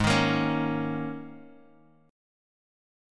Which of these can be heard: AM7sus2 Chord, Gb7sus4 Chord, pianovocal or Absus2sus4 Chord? Absus2sus4 Chord